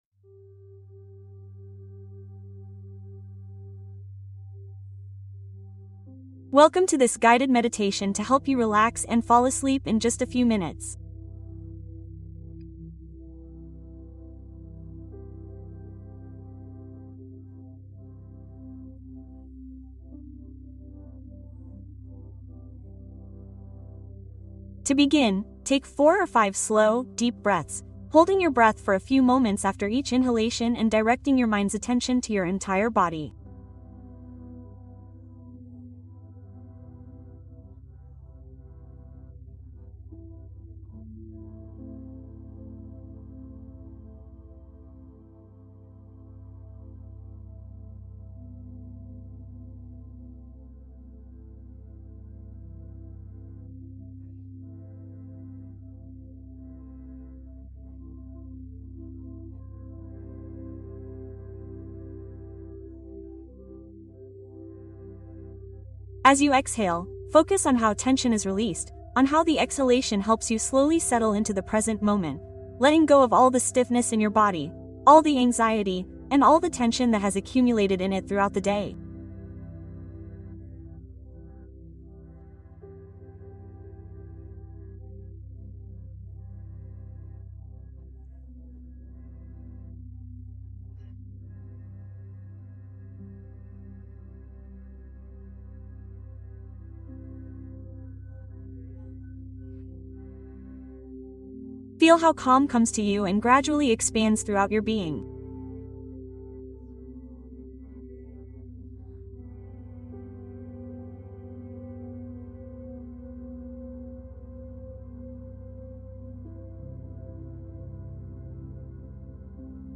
Sueño profundo Meditación para eliminar el insomnio y descansar